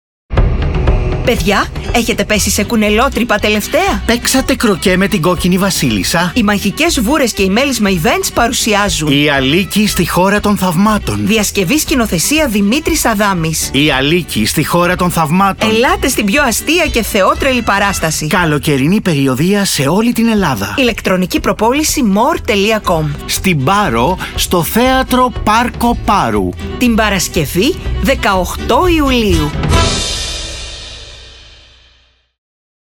ΗΧΗΤΙΚΟ-ΣΠΟΤ.mp3